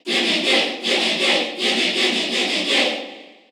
Category: Crowd cheers (SSBU) You cannot overwrite this file.
King_Dedede_Cheer_Korean_SSBU.ogg